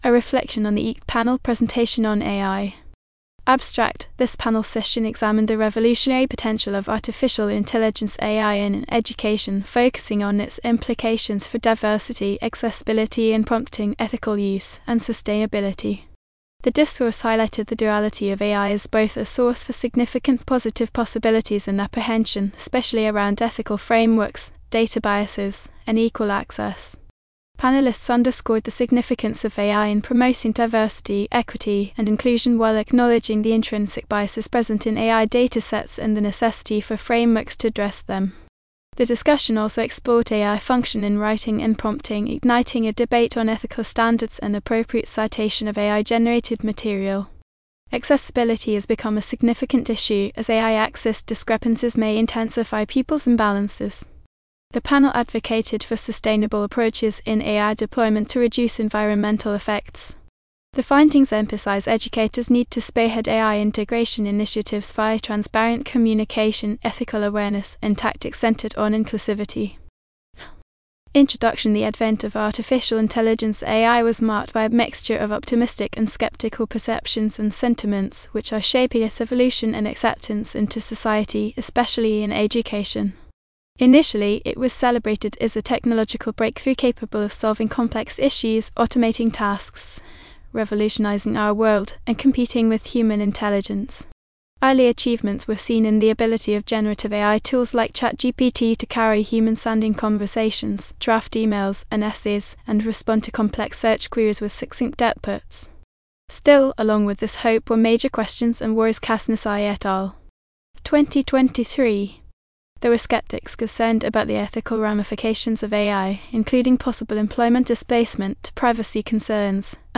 Abstract: This panel session examined the revolutionary potential of artificial intelligence (AI) in education, focusing on its implications for diversity, accessibility and prompting, ethical use, and sustainability. The discourse highlighted the duality of AI as both a source for significant positive possibilities and apprehension, especially around ethical frameworks, data biases, and equal access.